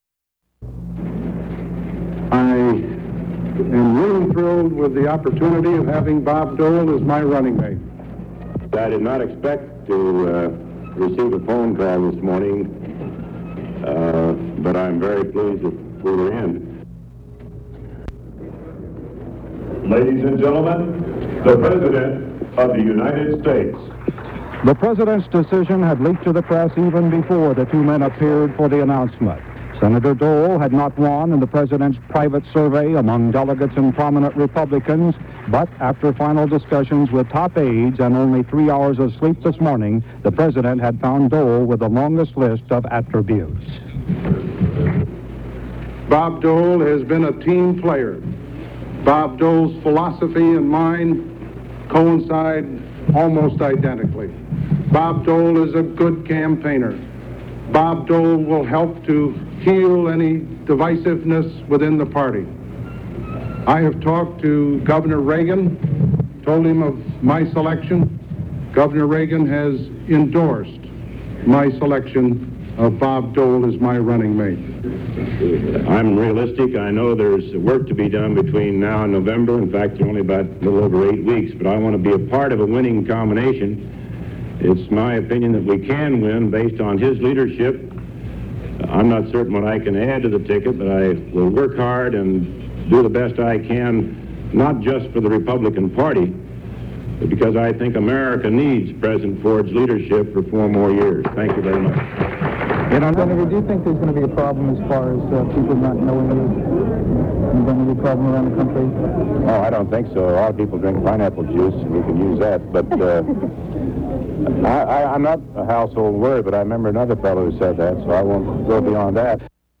Broadcast on CBS-TV, August 19, 1976.